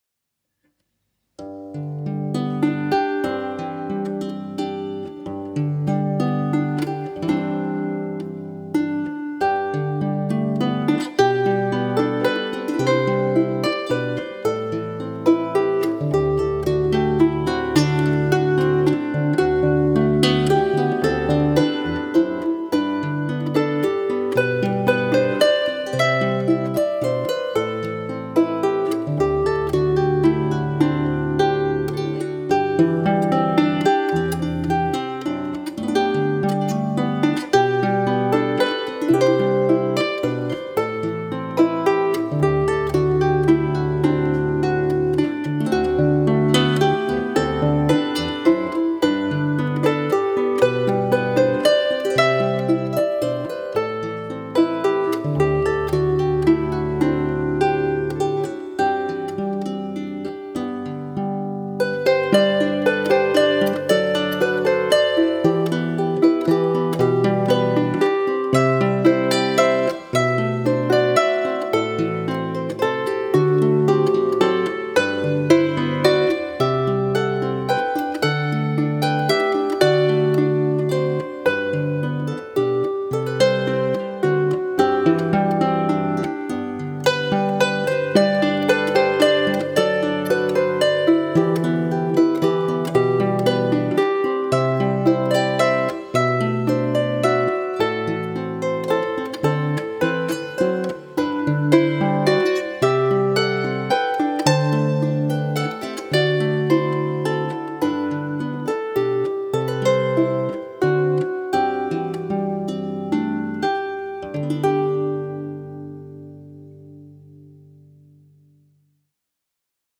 String Musicians
He is an absolute master of the Harp and Clarsach (small Scottish/Irish Harp),